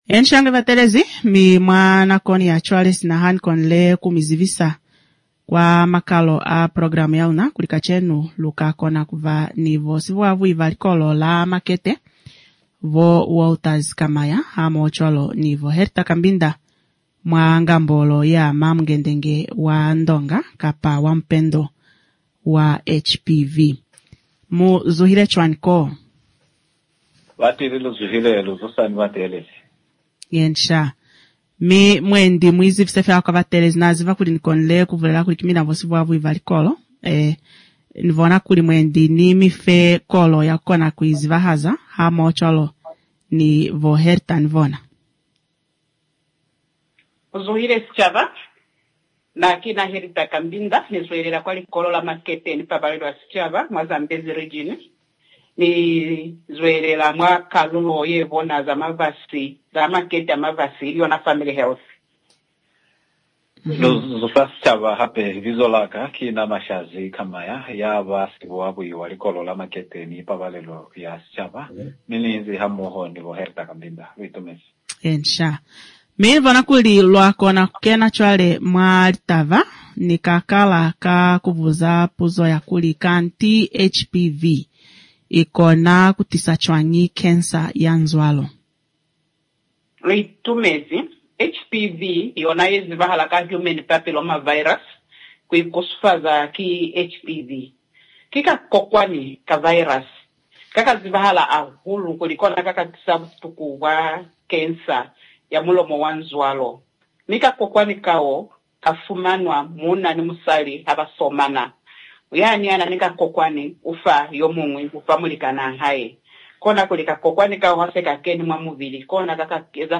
HPV AND CERVICAL CANCER SILOZI INTERVIEW (14 AUGUST 2025)